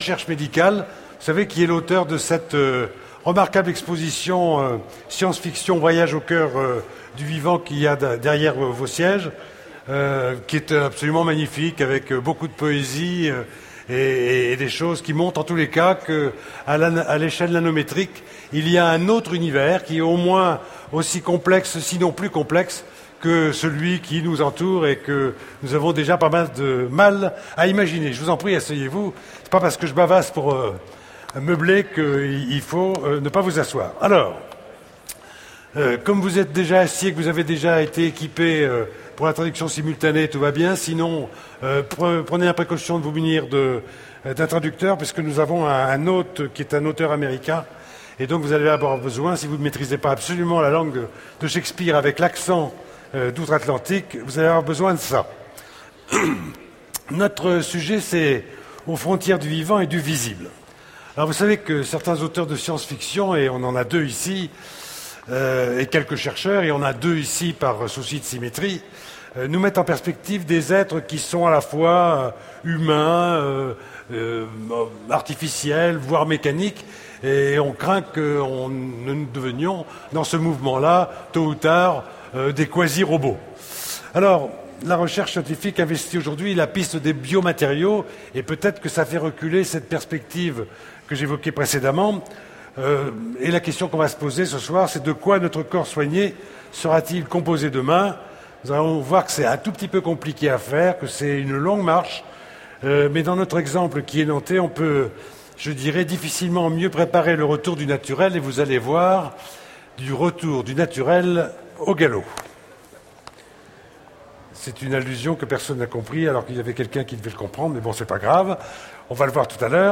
Utopiales 2010 : Conférence Inserm, Aux frontières du vivant et du visible
Voici l'enregistrement de la conférence " Aux frontières du vivant et du visible " aux Utopiales 2010.